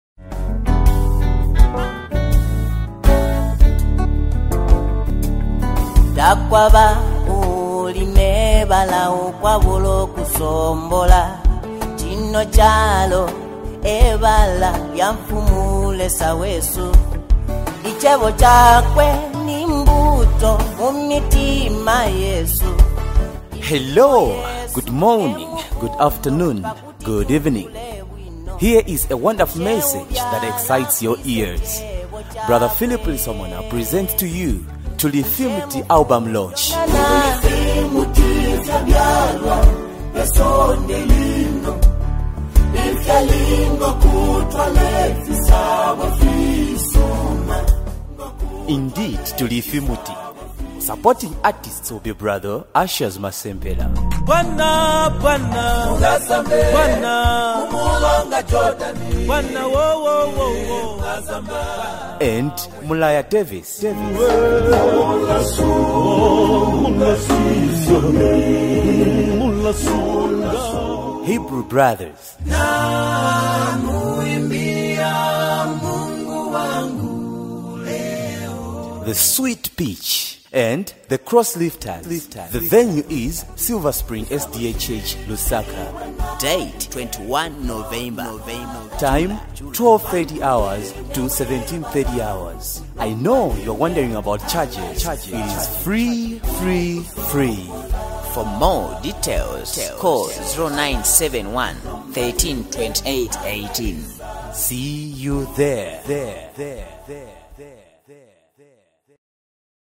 INSPIRATIONAL WORSHIP ANTHEM | 2025 ZAMBIA GOSPEL
With emotive vocals, engaging rhythms, and inspiring lyrics